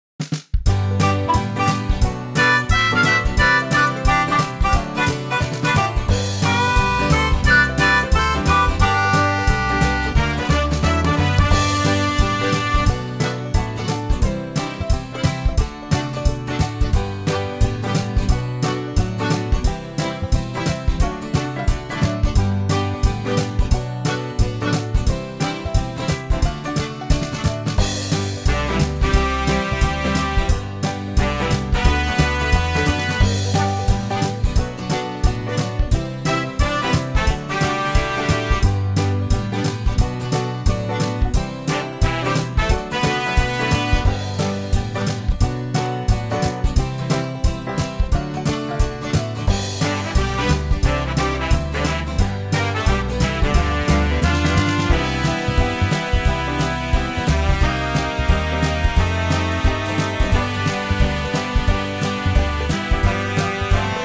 Unique Backing Tracks
key - G - vocal range - E to E
Suitable for typical male ranges.